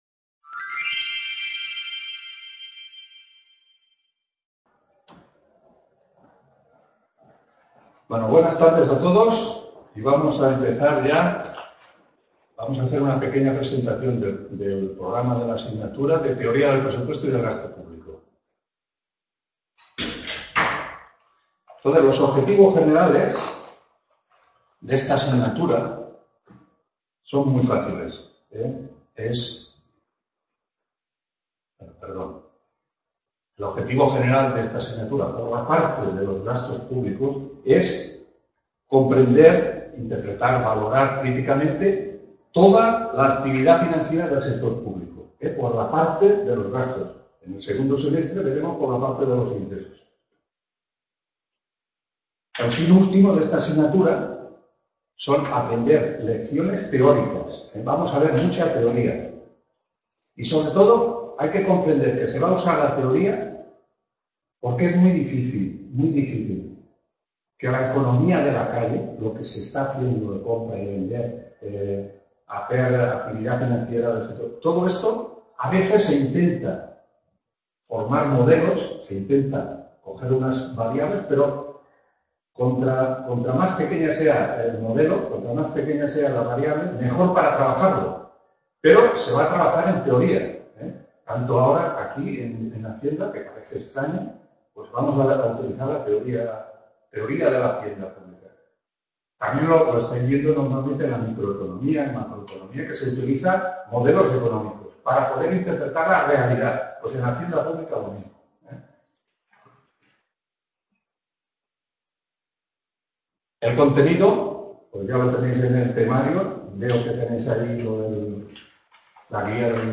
1ª TUTORÍA TEORÍA DEL PRESUPUESTO Y DEL GASTO PÚBLICO…